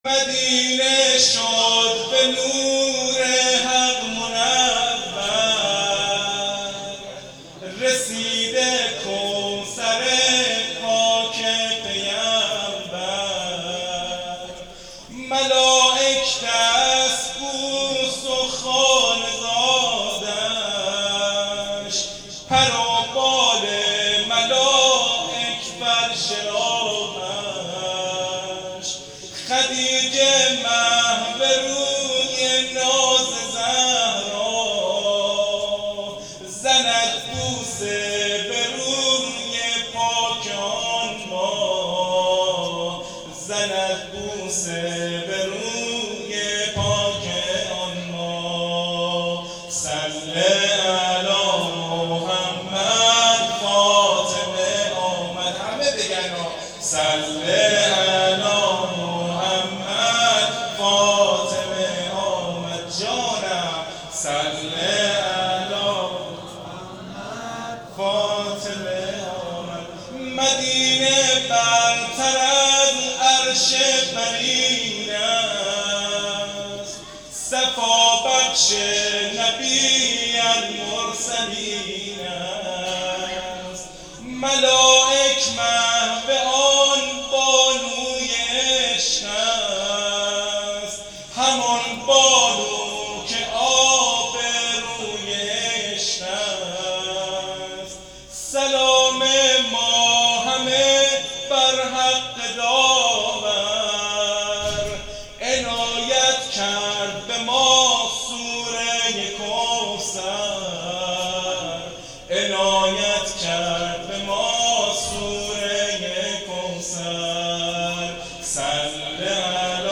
سرود صلوات علی محمد ، فاطمه آ مد